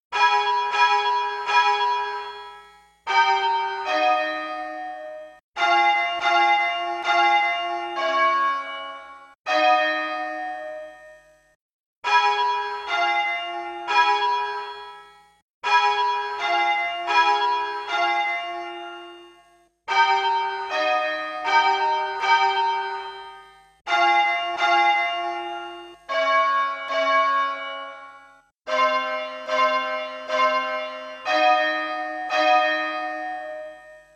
This is still a little uninteresting so to develop it further, change the pair of bells for each subsequent letter.